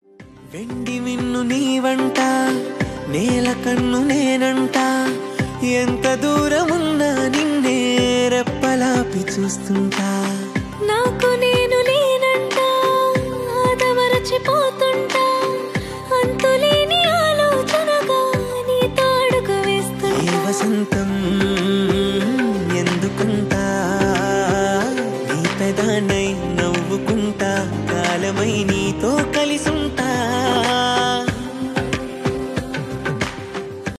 Trending Telugu BGM ringtone for mobile.